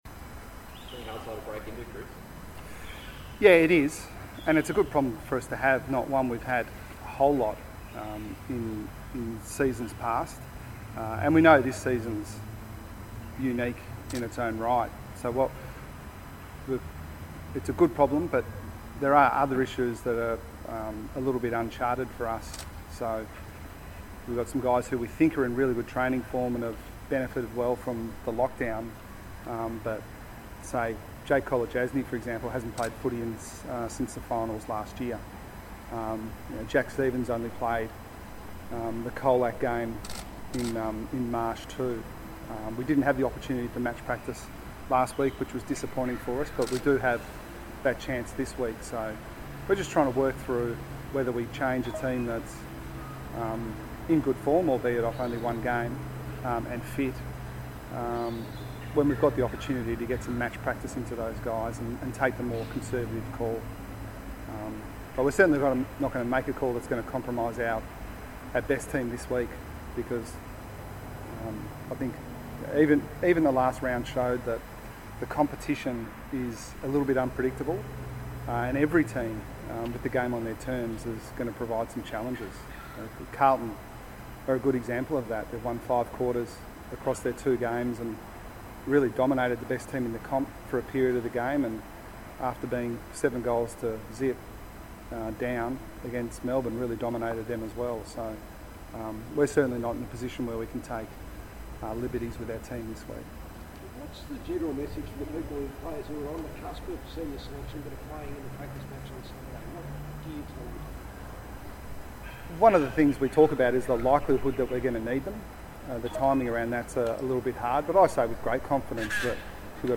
Geelong coach Chris Scott faced the media ahead of Saturday's clash with Carlton.